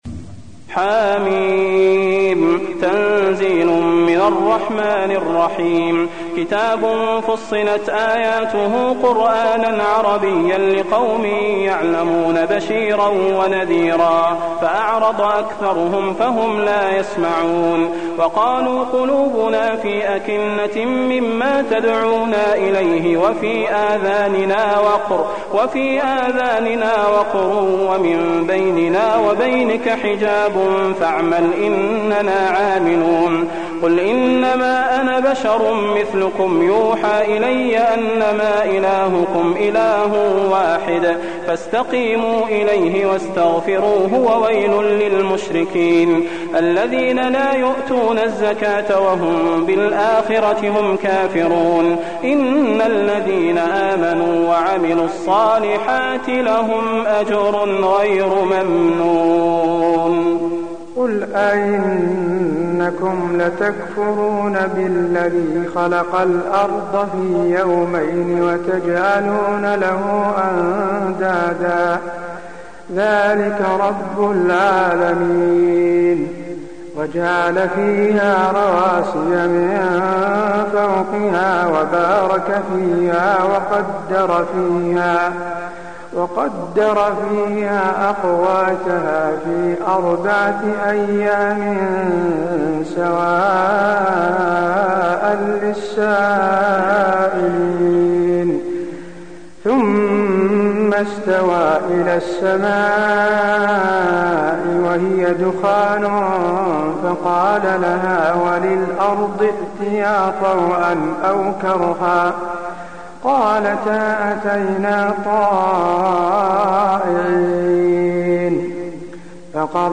المكان: المسجد النبوي فصلت